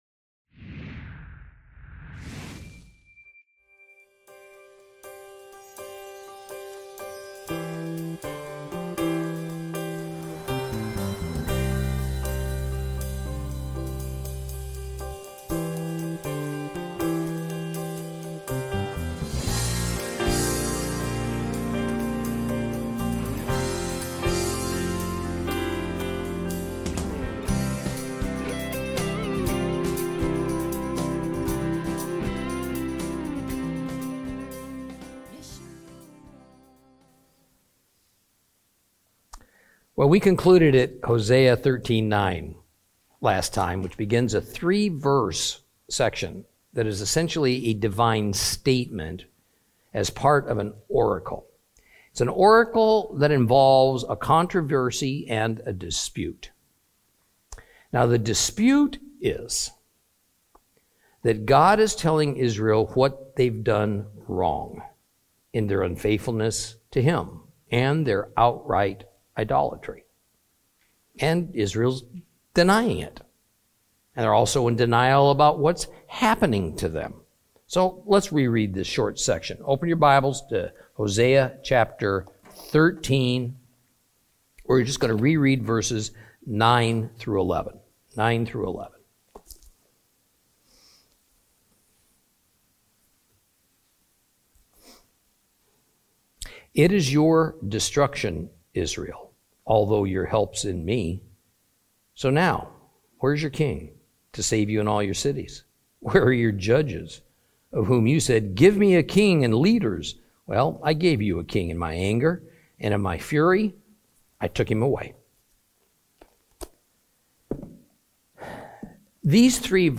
Teaching from the book of Hosea, Lesson 23 Chapters 13 and 14.